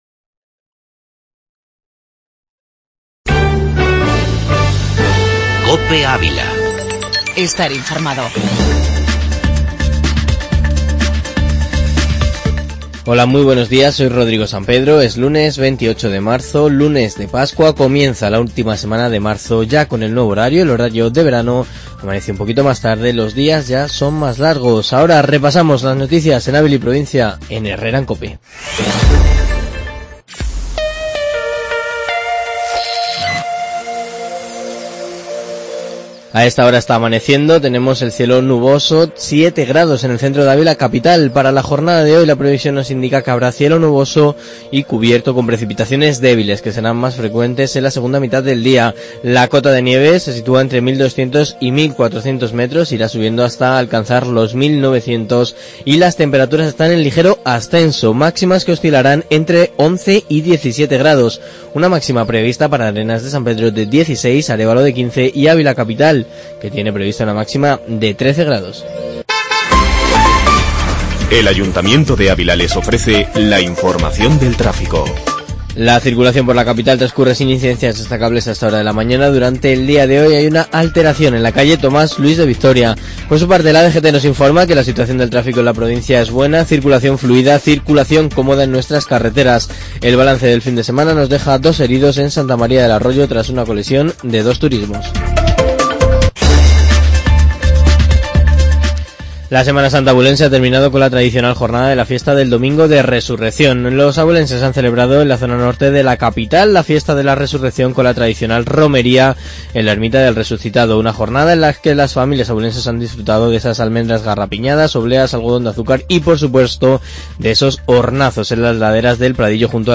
Informativo La Mañana en Ávila